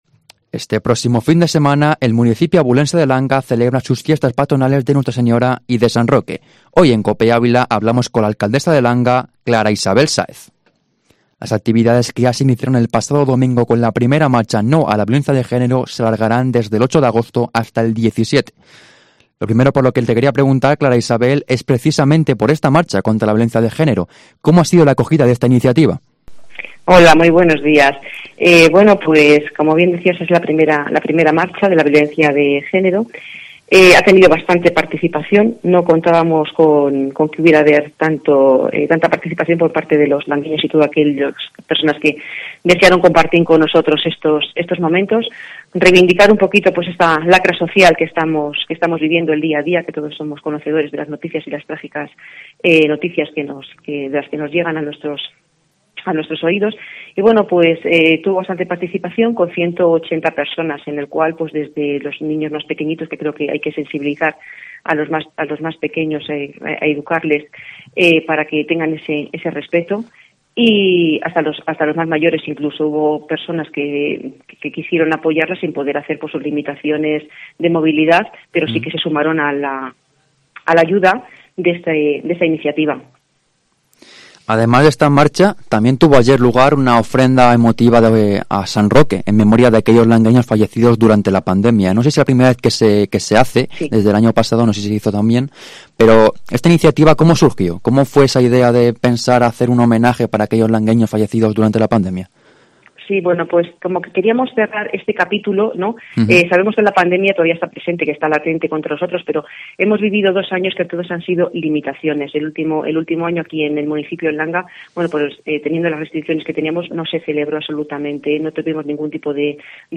Entrevista Clara Isabel Sáez alcaldesa Langa